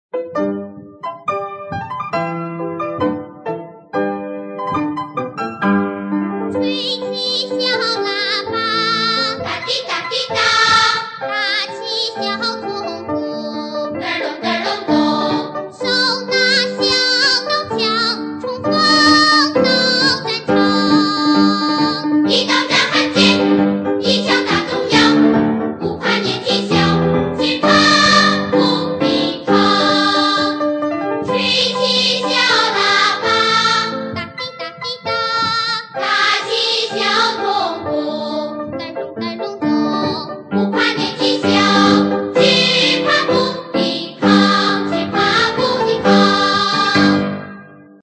中央人民广播电台少年广播合唱团演唱 刘诗昆钢琴伴奏